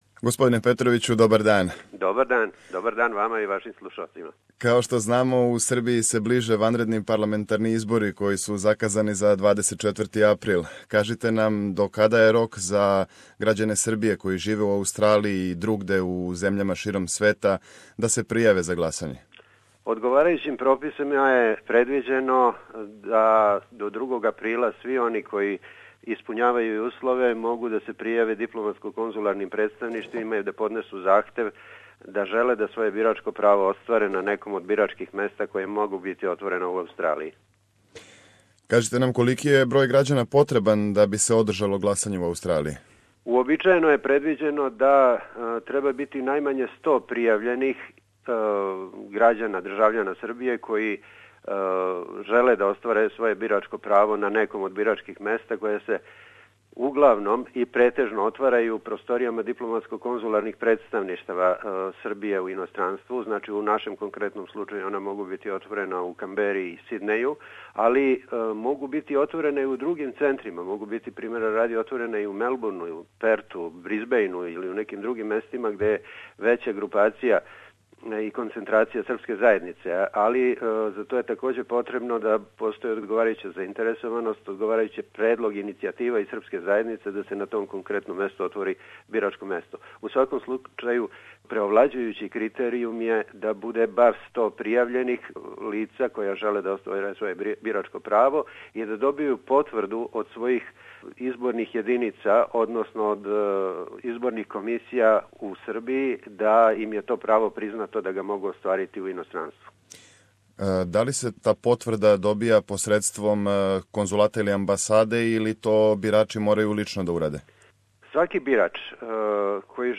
Послушајте цео интервју с амбасадором Петровићем.